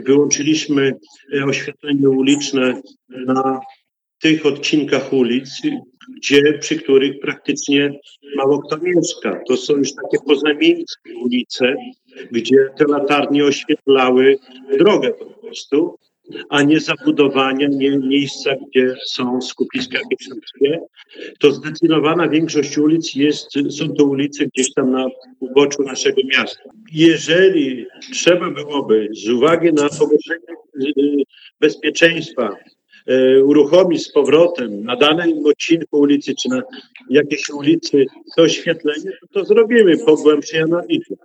Sprawa była przedmiotem dyskusji podczas ostatniej sesji Rady Miejskiej.
W odpowiedzi Czesław Renkiewicz, prezydent miasta zauważył, że oświetlenie wygaszane jest na ulicach, przy których mało kto mieszka. Zapewnił, że jednak zajdzie potrzeba, miasto przywróci oświetlenie.